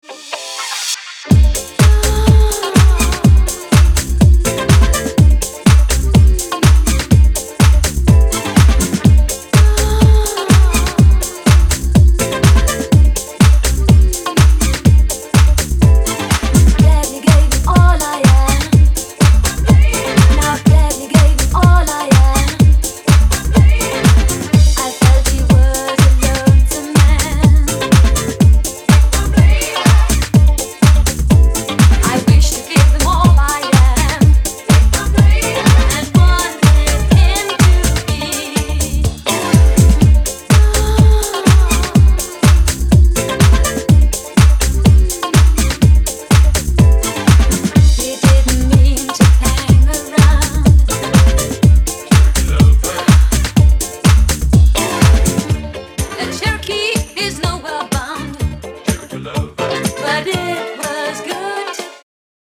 各曲、煽りに煽るフィルター使いとマッシヴなボトムの威力が光ります。